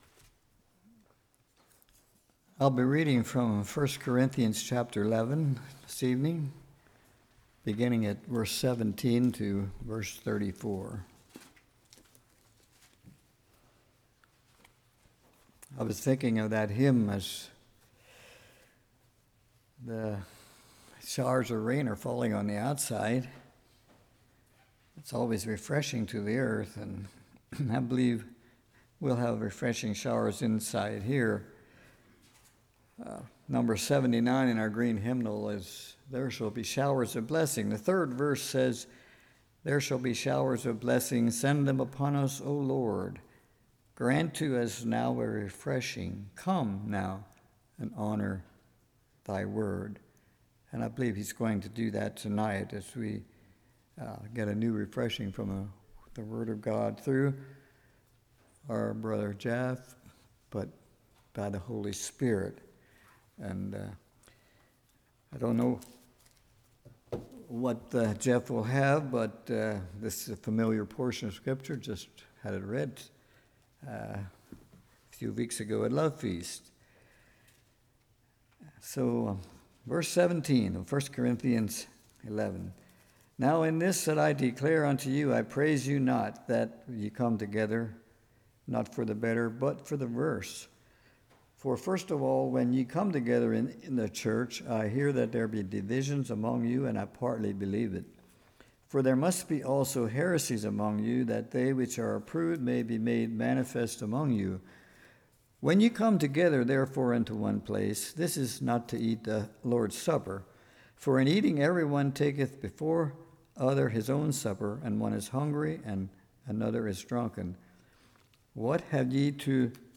1 Corinthians 11:17-34 Service Type: Evening Establishing Communion Church’s View Meaning of Communion « What Manner of Man is This?